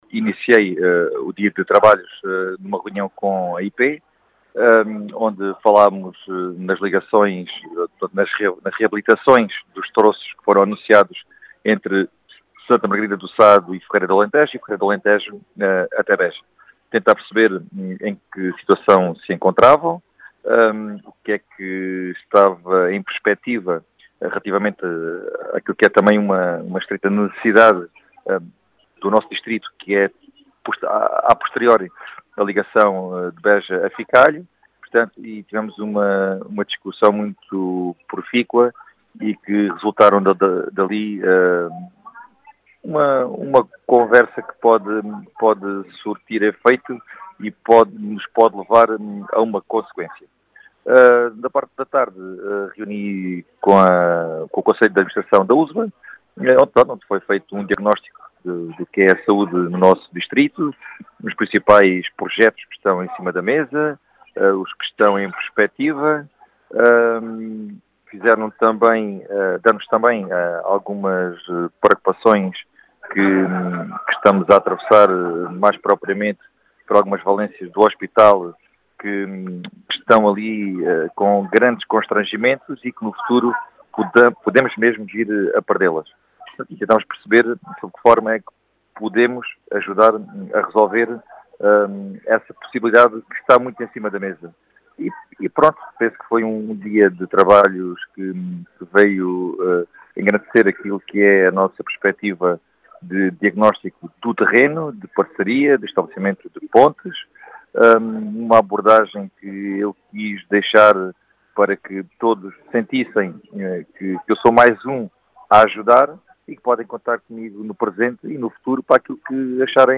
As explicações foram deixadas na Rádio Vidigueira pelo deputado do PSD, Gonçalo Valente, que fala de um dia de trabalho de “diagnóstico e parcerias” para o futuro.